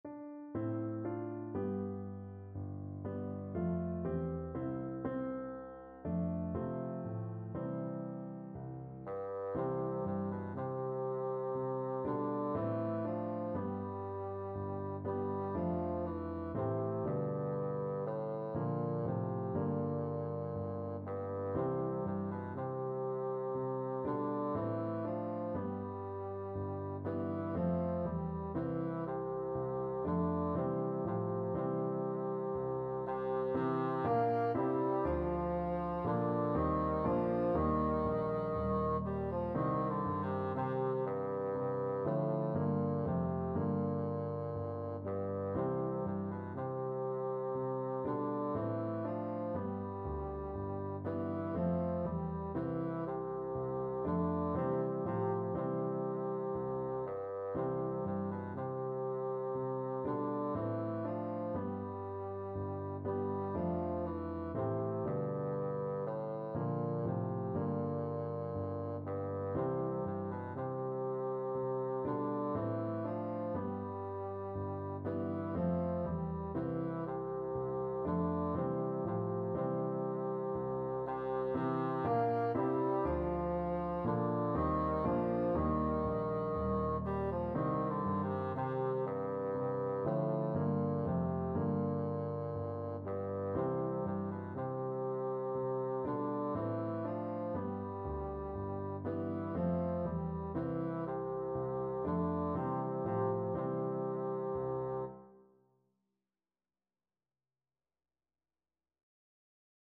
Christmas Christmas Bassoon Sheet Music The Wexford Carol
Bassoon
C major (Sounding Pitch) (View more C major Music for Bassoon )
Slow, expressive =c.60
3/4 (View more 3/4 Music)
Classical (View more Classical Bassoon Music)
wexford_carol_BN.mp3